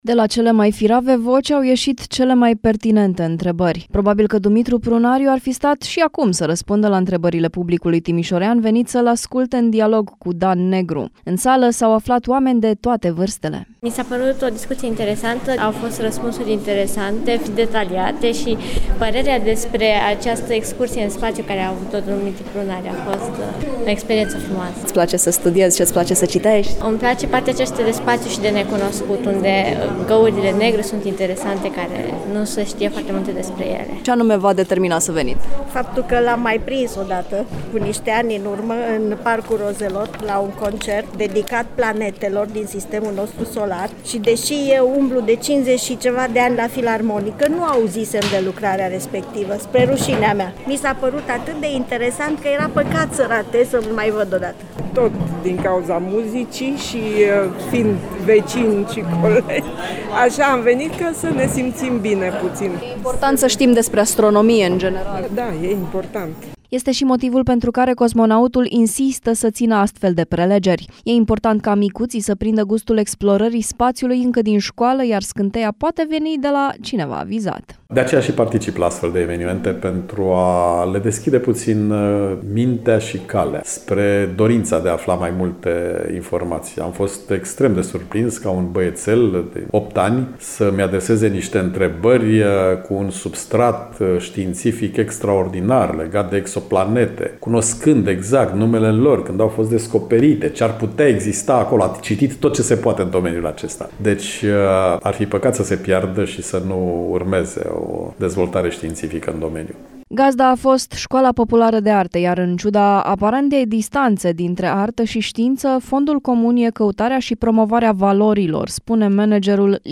Dumitru Prunariu a fost prezent, la Timișoara, unde a participat la seminarul intitulat ”Despre lumea de deasupra noastră”.